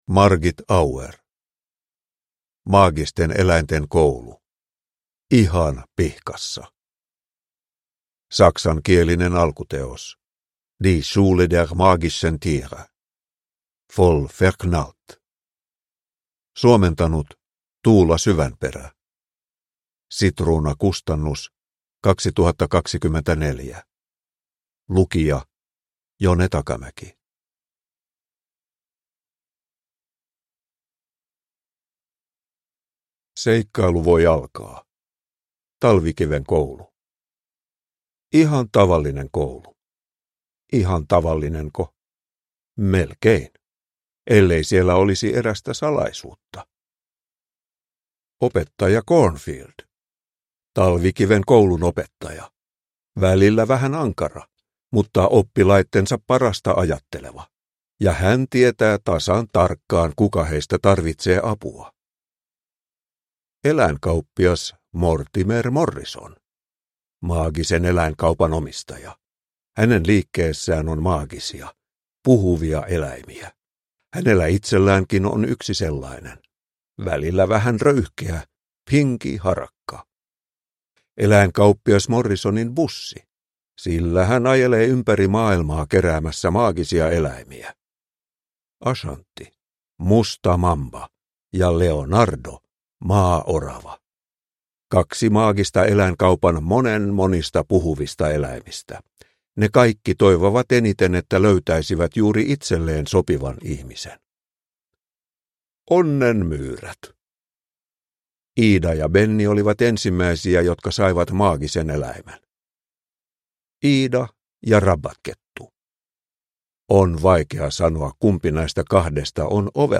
Maagisten eläinten koulu 8 - Ihan pihkassa! – Ljudbok